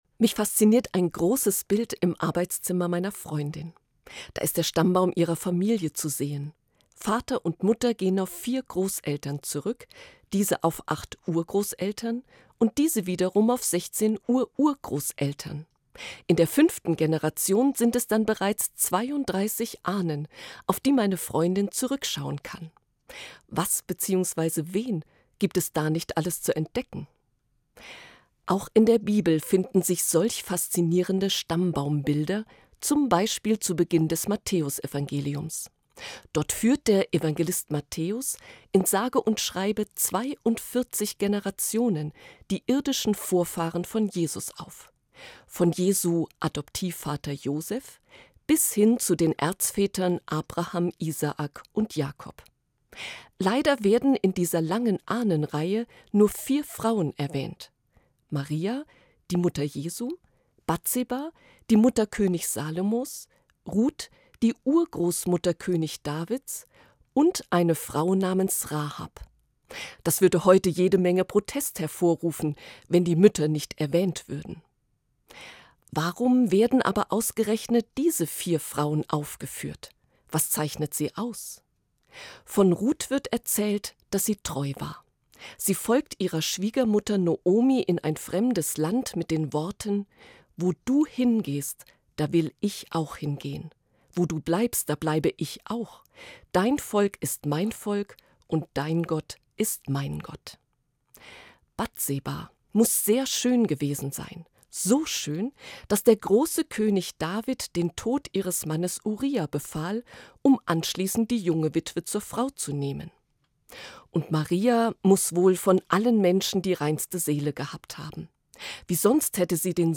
hr2 MORGENFEIER